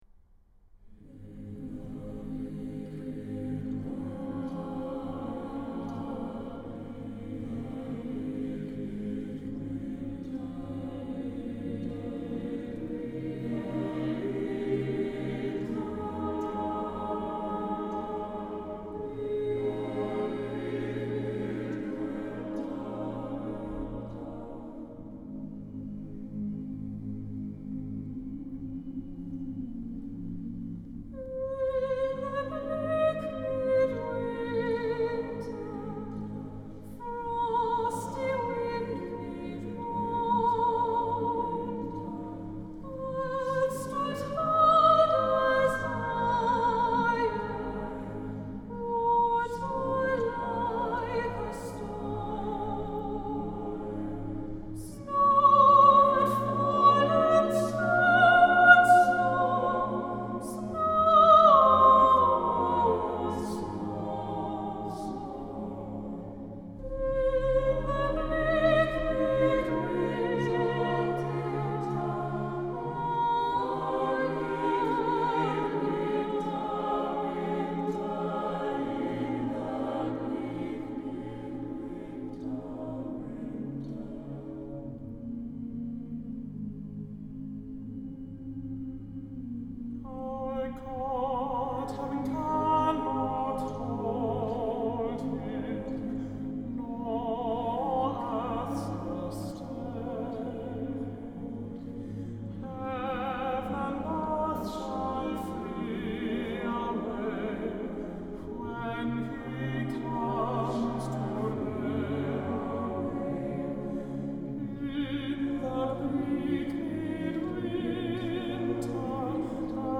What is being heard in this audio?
Voicing: SSAATTBB